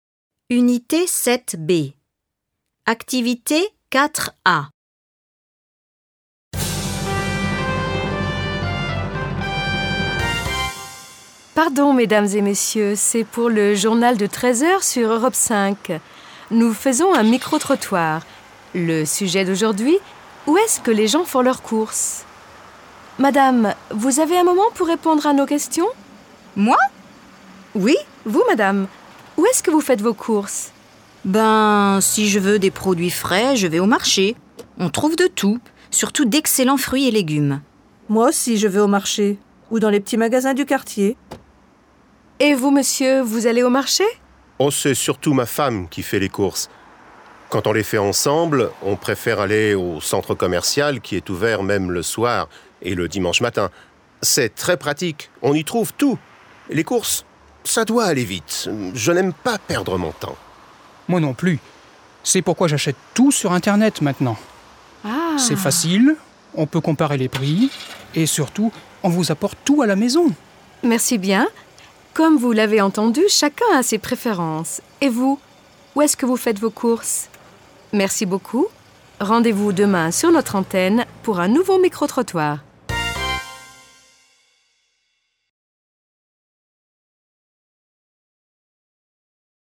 Micro-trottoir.